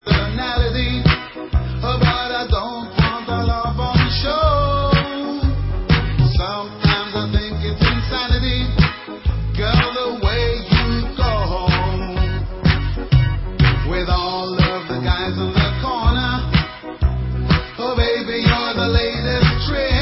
World/Reggae